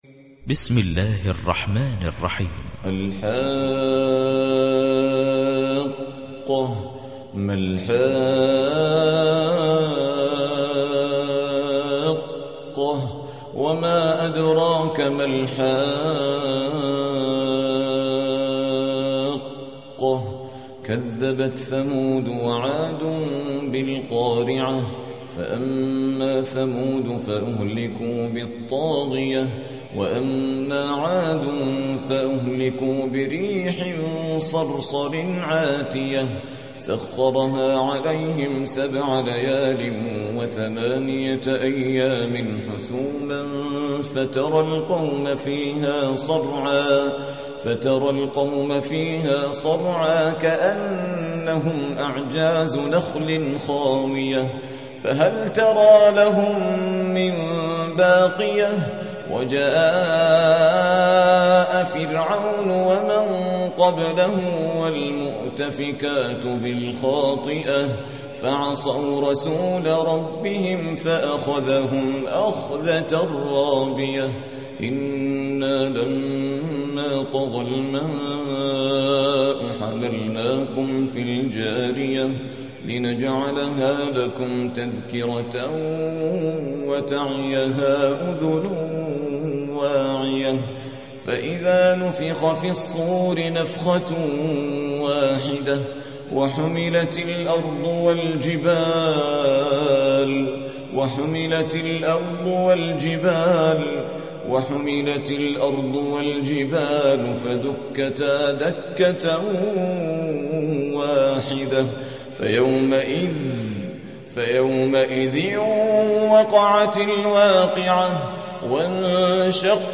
69. سورة الحاقة / القارئ